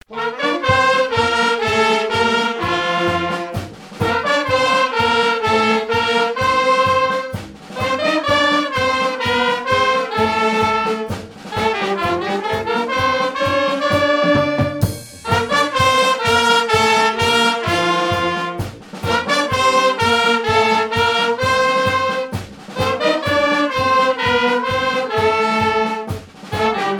gestuel : à marcher
Pièce musicale inédite